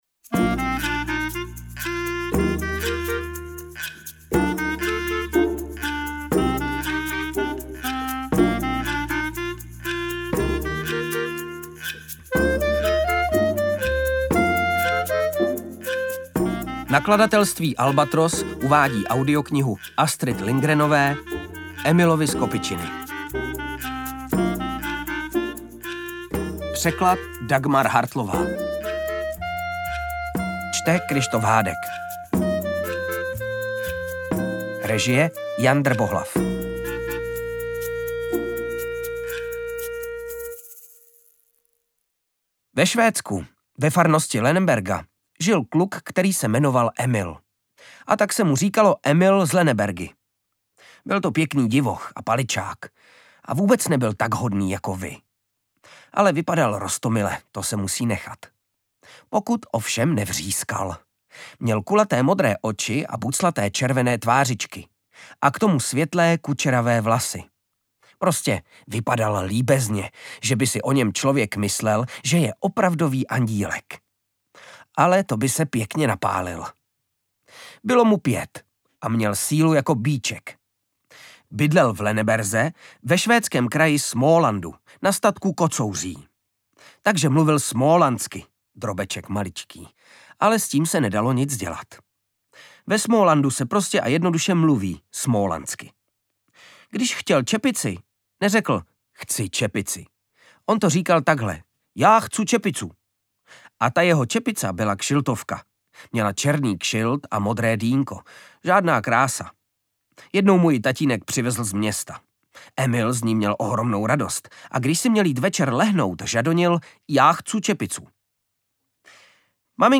Audiobook
Read: Kryštof Hádek